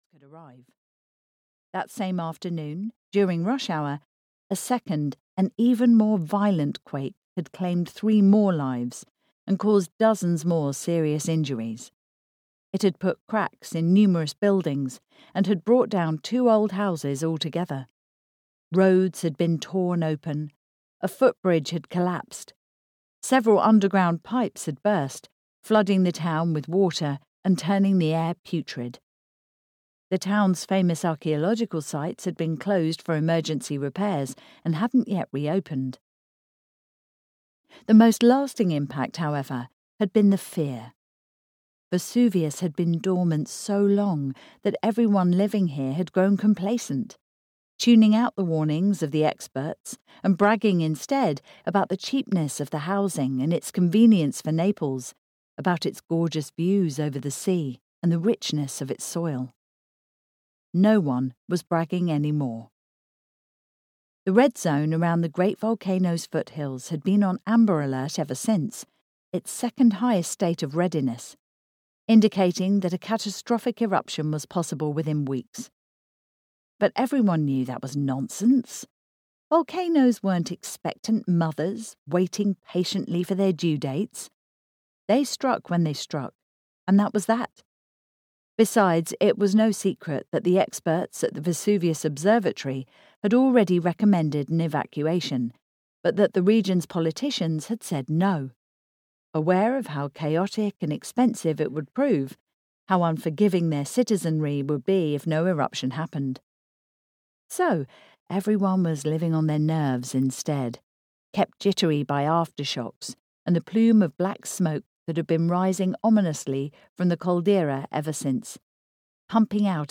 The Heretic Scroll (EN) audiokniha
Ukázka z knihy